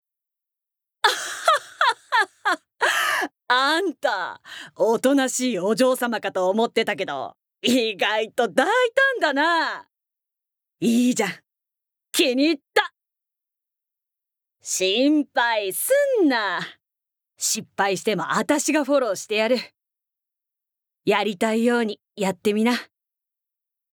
Voice Sample
ボイスサンプル
セリフ６